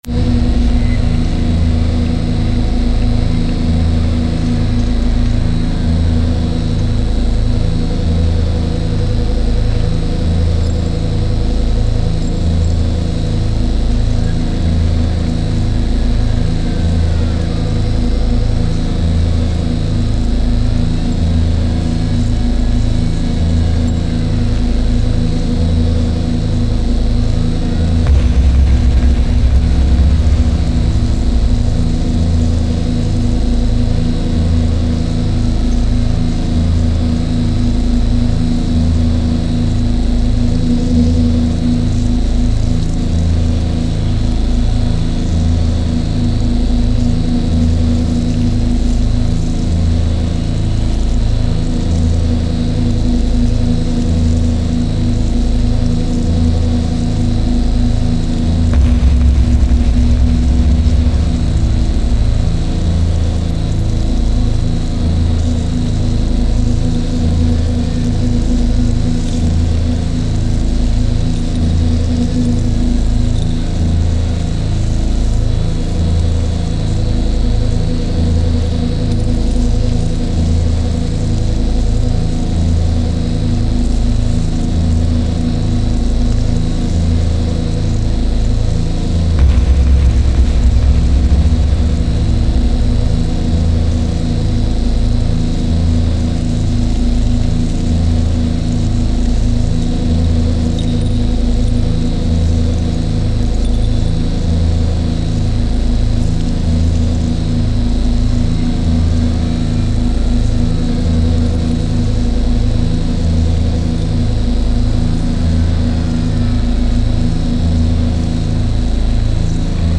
File under: Industrial / Experimental